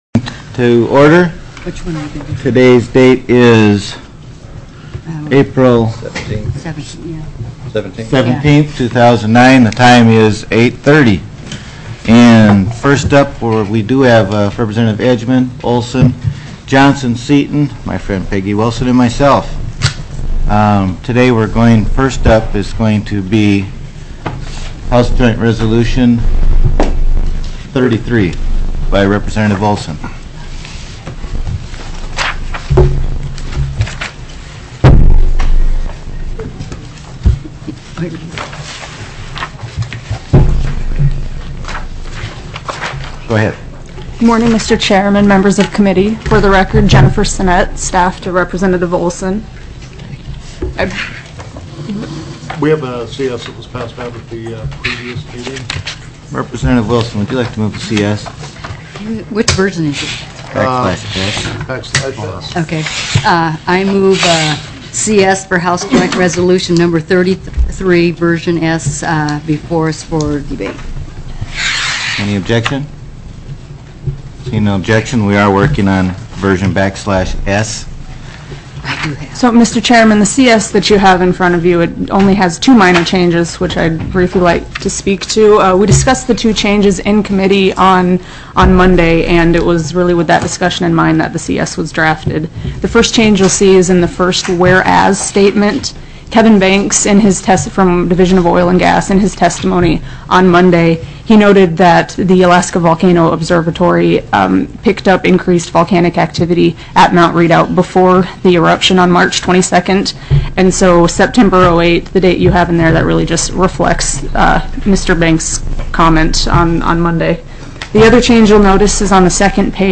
ALASKA STATE LEGISLATURE HOUSE RESOURCES STANDING COMMITTEE April 17, 2009 8:31 a.m.